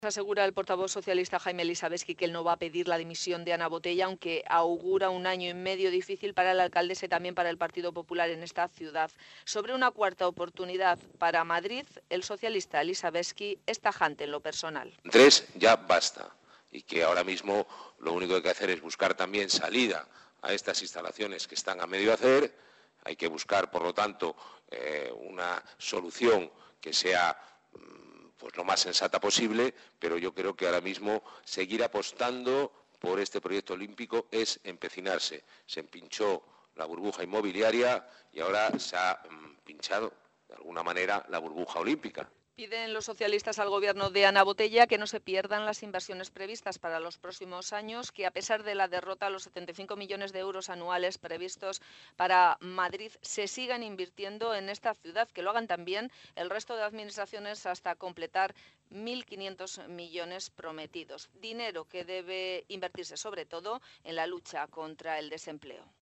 En una rueda de prensa en la que ha analizado el inicio del curso político, Lissavetzky ha dicho que por haber perdido Madrid los Juegos Olímpicos no pedirá el PSOE la dimisión de Botella, algo que -ha dicho- ya pidió en el caso Madrid Arena.